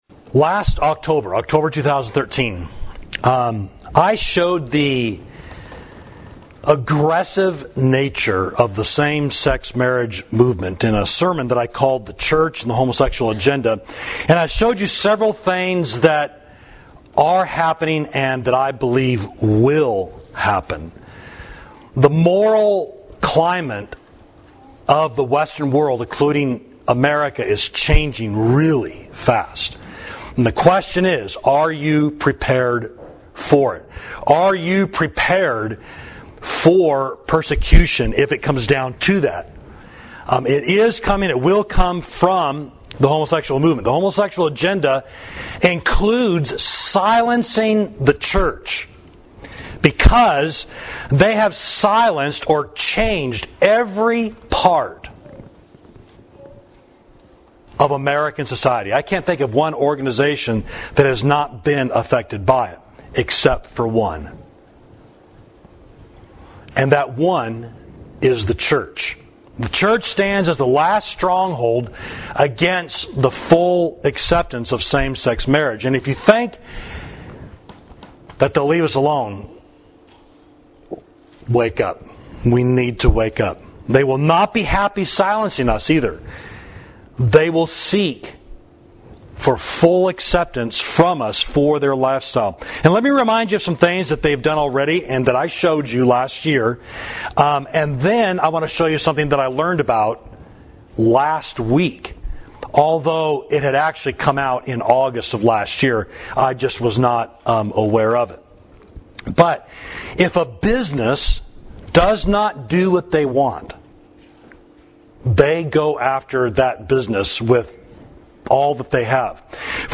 Sermon: Are You Ready for Persecution?